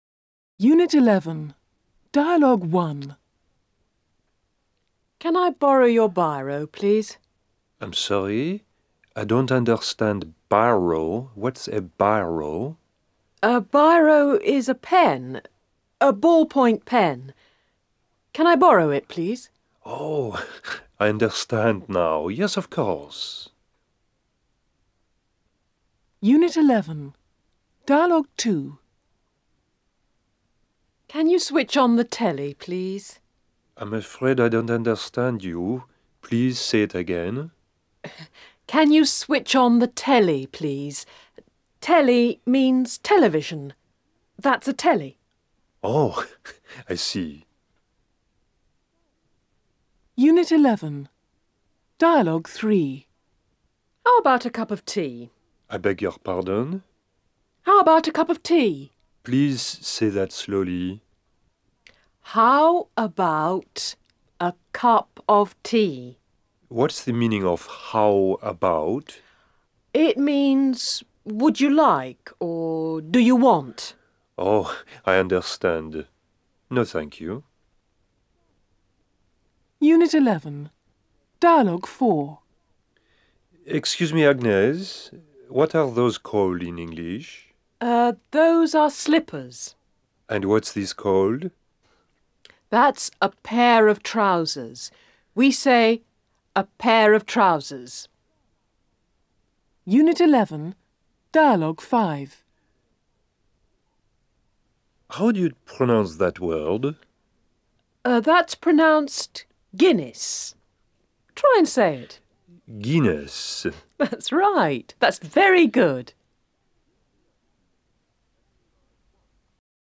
01 - Unit 11, Dialogues.mp3